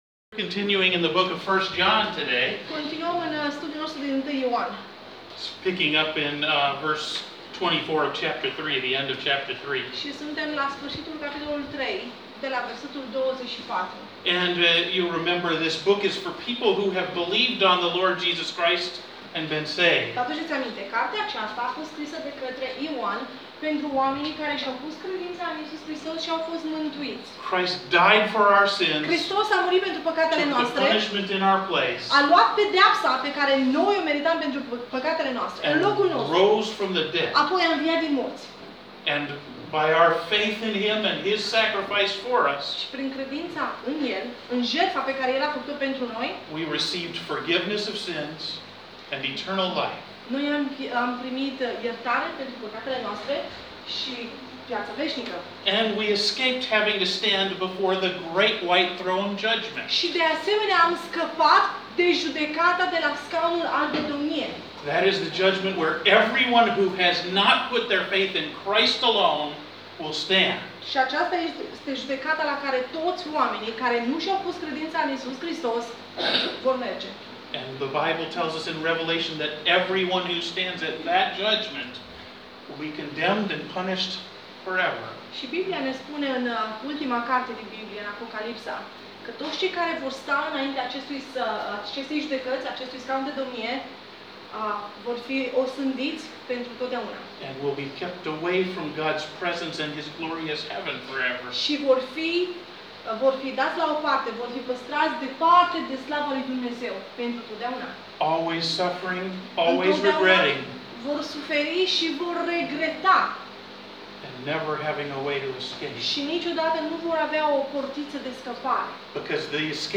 Sermon Audio 1 Ioan 3:24-4:6 == 23 iunie